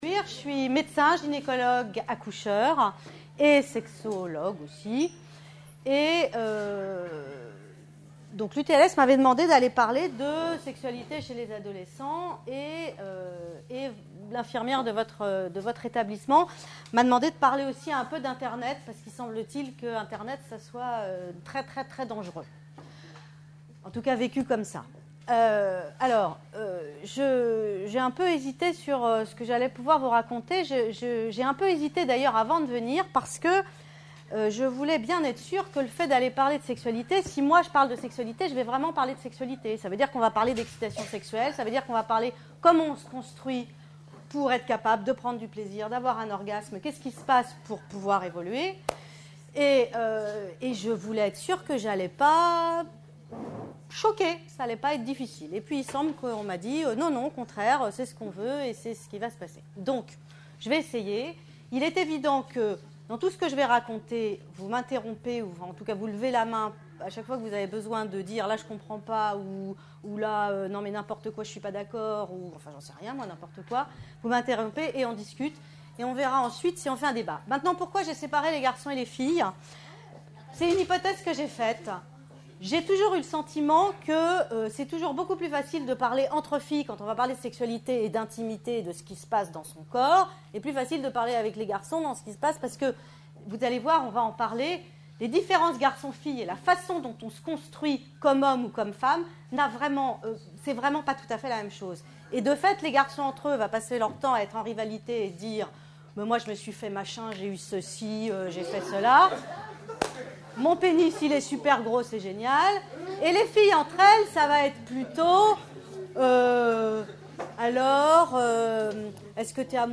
Une conférence de l'UTLS au lycée La sexualité chez les adolescents (pratiques d'internet)